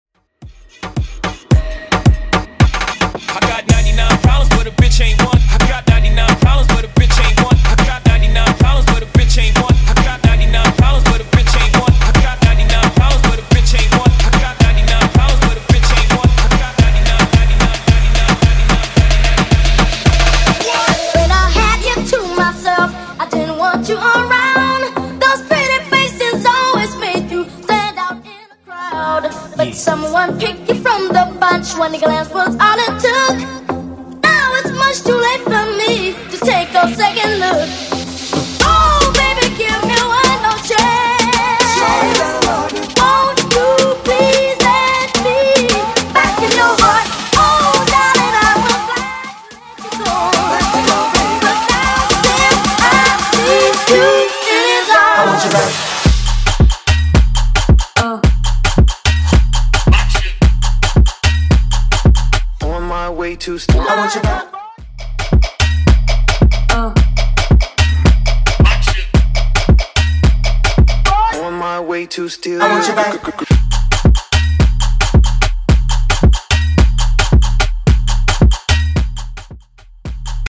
DANCE , PARTY BREAKS , TOP40 88 Clean